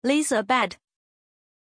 Aussprache von Lisabet
pronunciation-lisabet-zh.mp3